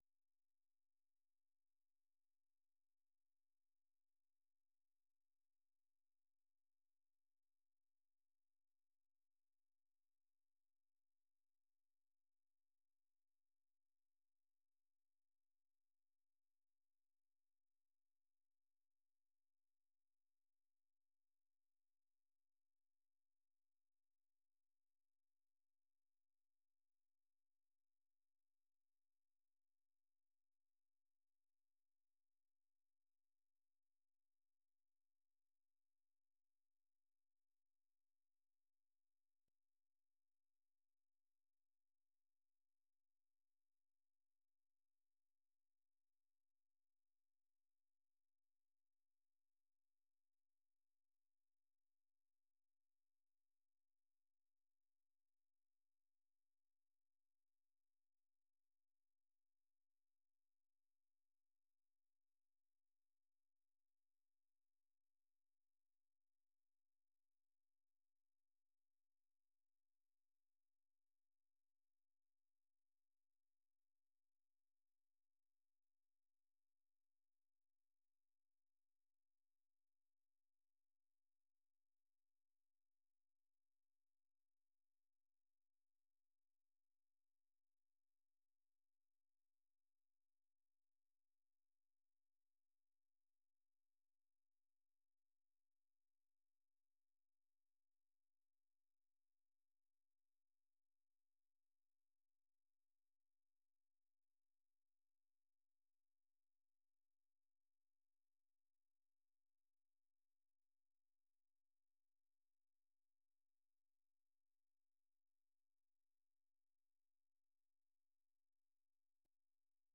The URL has been copied to your clipboard 페이스북으로 공유하기 트위터로 공유하기 No media source currently available 0:00 0:59:58 0:00 생방송 여기는 워싱턴입니다 생방송 여기는 워싱턴입니다 공유 생방송 여기는 워싱턴입니다 share 세계 뉴스와 함께 미국의 모든 것을 소개하는 '생방송 여기는 워싱턴입니다', 저녁 방송입니다.